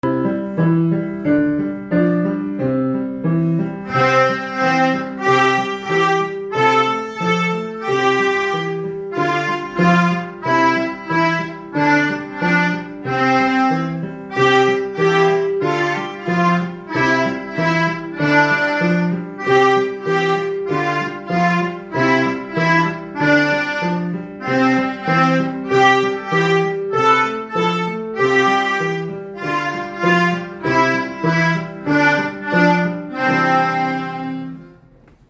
♪きらきらぼし演奏♪.mp3